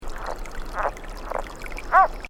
It is a short and rasping call often accellerated and rising at the end, sometimes preceeded by calls that don't rise at the end. This frog has no vocal sacs, so the call has very little volume.
Sound  This is a recording of the advertisement calls of a Sierra Yellow-legged Frog recorded during the day in Alpine County.